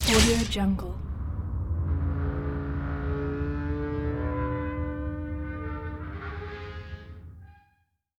دانلود افکت صدای برخورد لیزر به فلز ۳
افکت صدای برخورد لیزر به فلز 3 یک گزینه عالی برای هر پروژه ای است که به صداهای صنعتی و جنبه های دیگر مانند سوزاندن، متالیک و ضربه نیاز دارد.
Sample rate 16-Bit Stereo, 44.1 kHz
Looped No